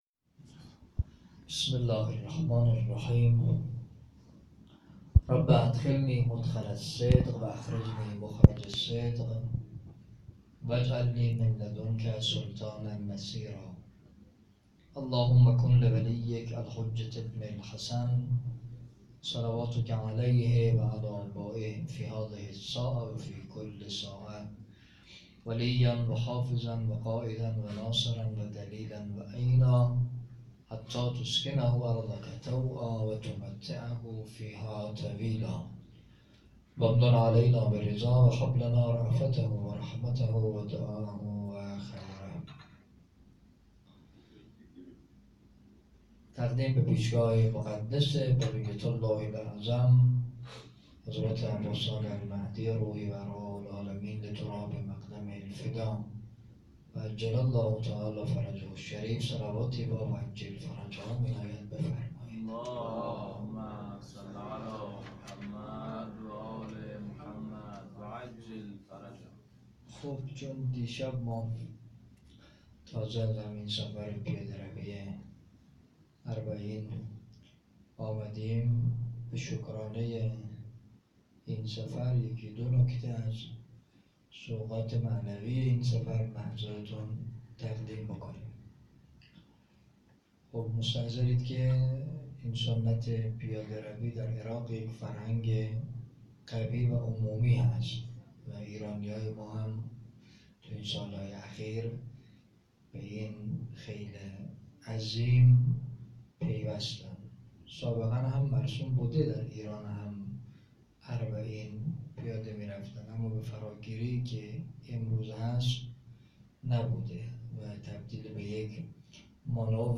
5 آذر 95 - بیت النور - سخنرانی